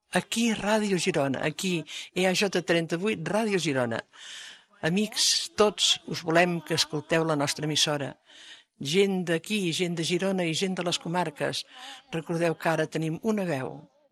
Recreació de les paraules inaugurals.